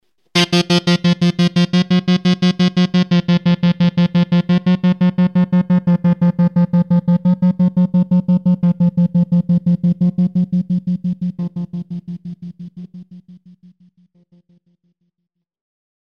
Téma: "tremolo" efekt a bicí
Tady to je 5,8 Hz.